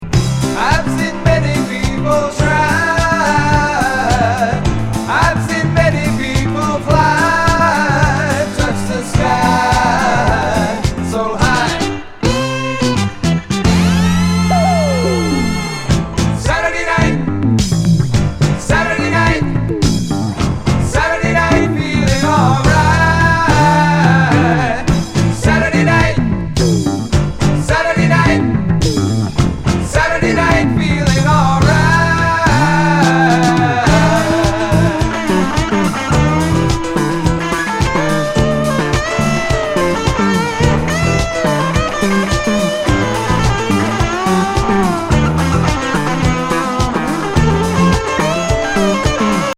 英語詞ファンキー・グルーヴ!